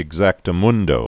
(ĭg-zăktə-mŭndō)